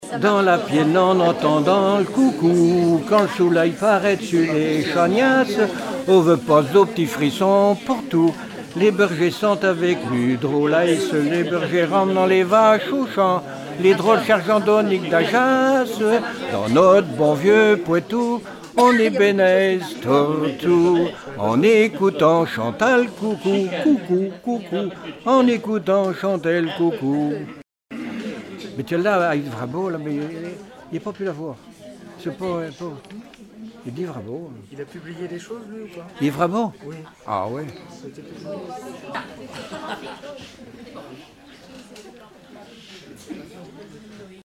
Genre brève
Répertoire de chansons populaires et traditionnelles
Catégorie Pièce musicale inédite